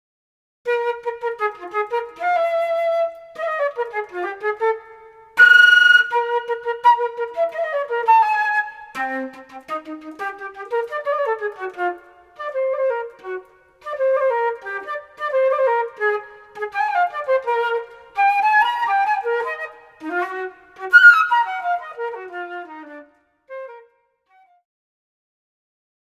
Categories » Flute » Solo Flute